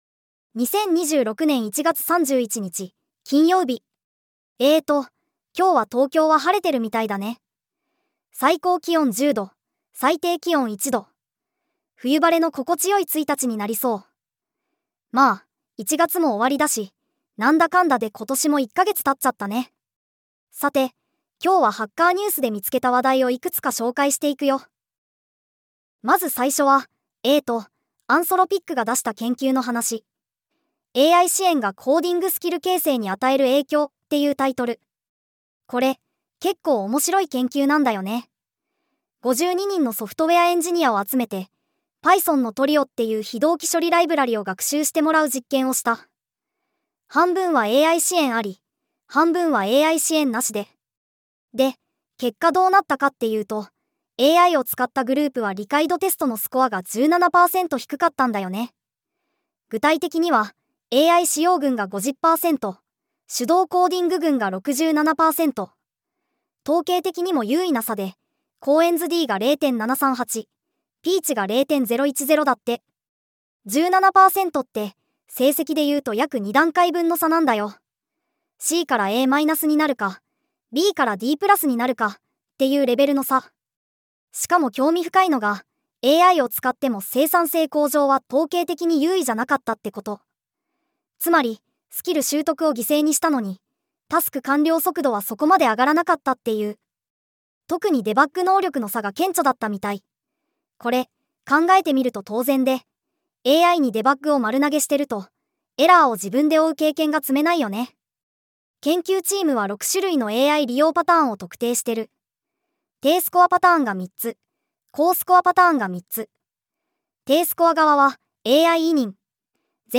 テトさんに技術系ポッドキャストを読んでもらうだけ
音声: VOICEPEAK 重音テト キャラクター